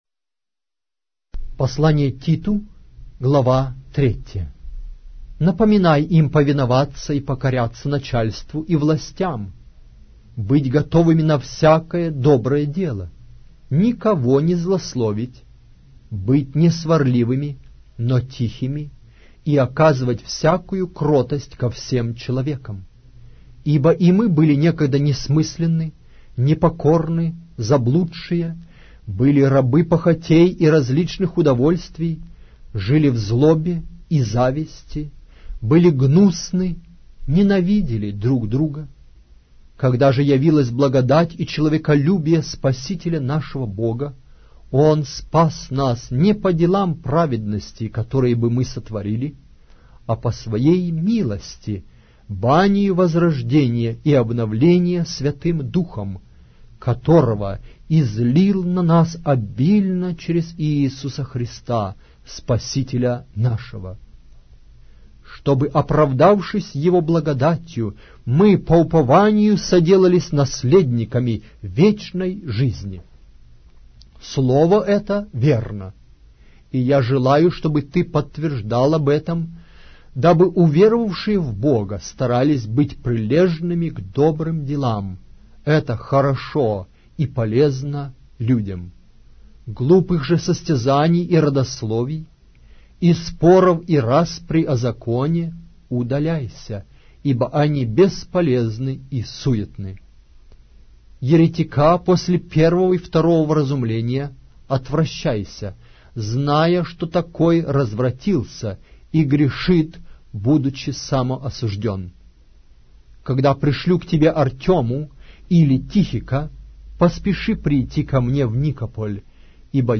Аудиокнига: Послн.св.Апостола Павла к Титу